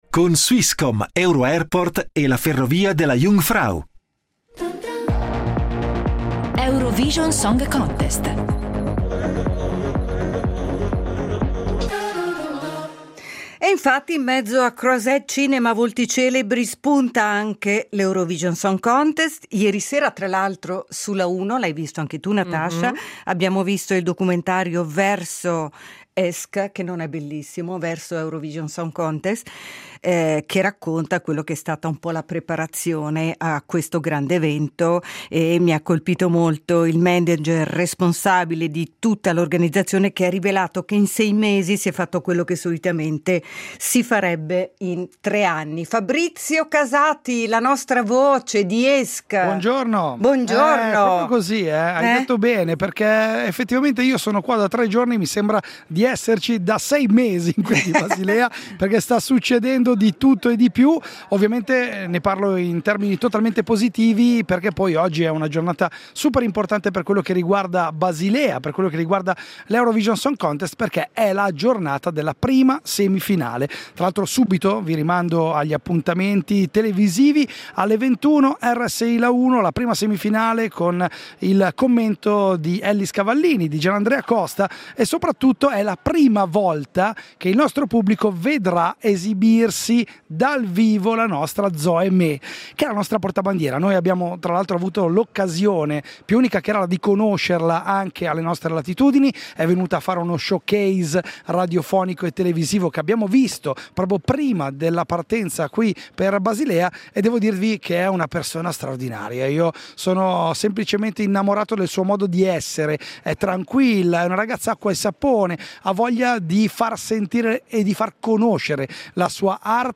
in diretta da Basilea